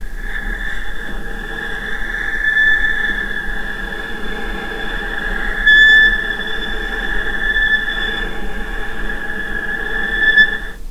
vc_sp-A6-pp.AIF